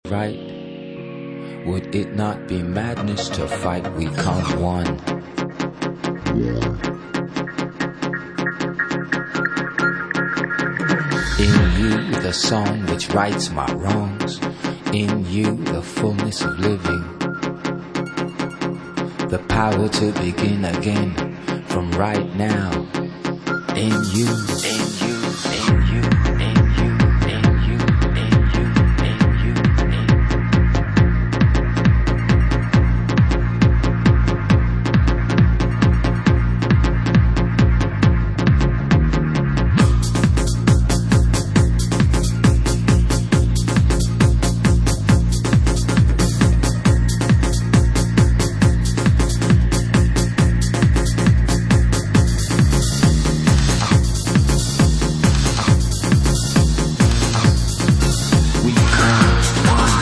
Styl: House, Lounge, Breaks/Breakbeat